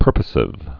(pûrpə-sĭv)